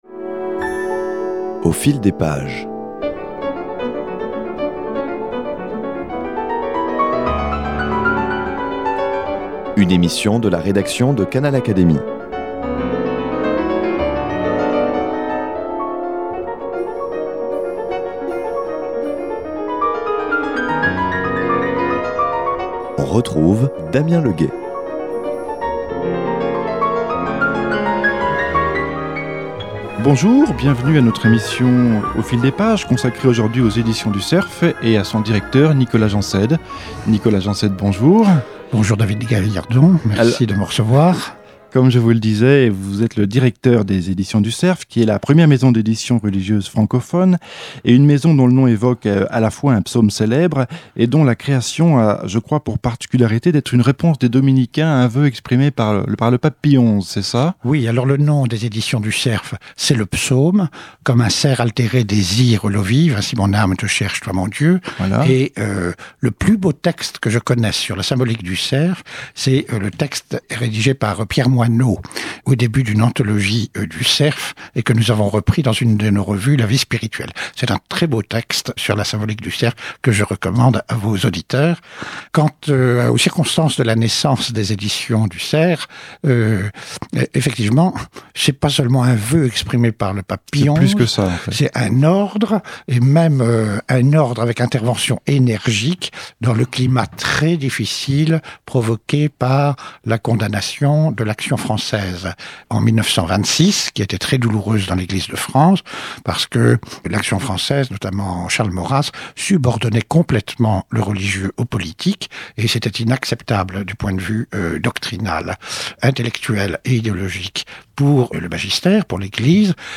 Les éditions du Cerf, entretien